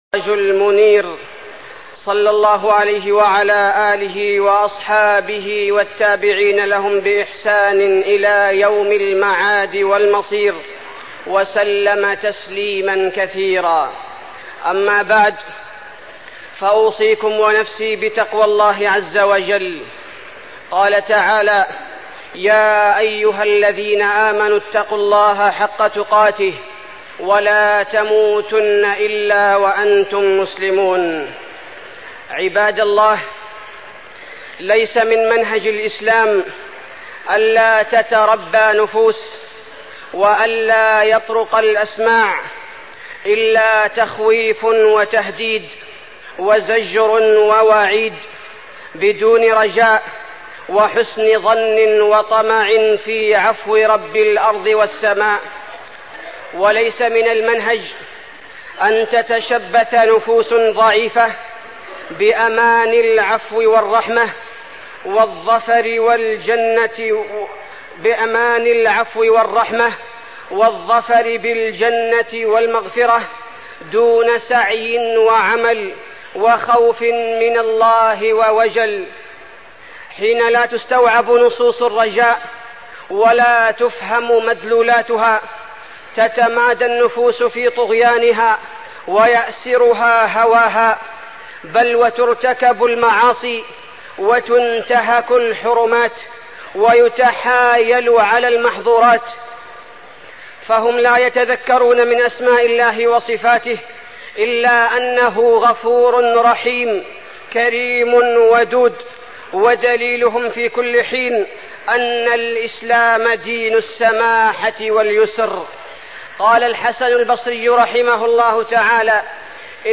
تاريخ النشر ٢٣ ذو الحجة ١٤١٦ هـ المكان: المسجد النبوي الشيخ: فضيلة الشيخ عبدالباري الثبيتي فضيلة الشيخ عبدالباري الثبيتي الخوف من الله عز وجل